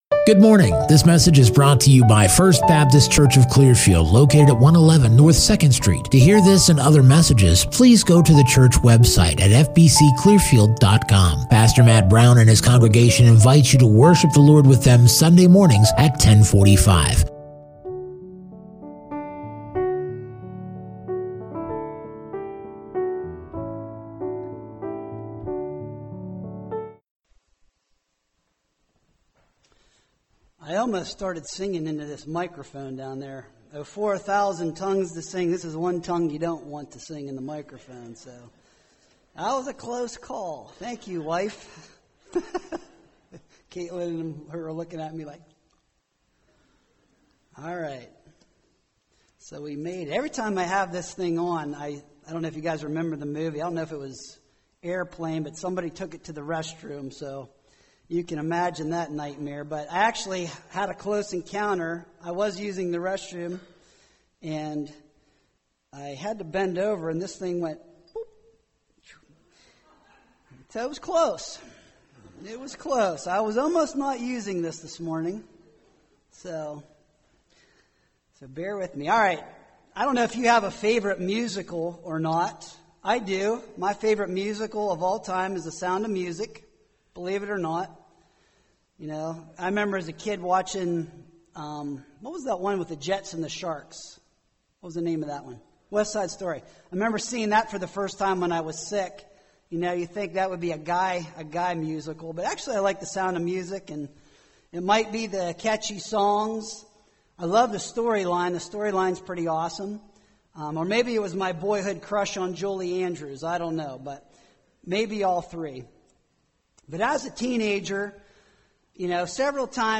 Non-Series Sermon Passage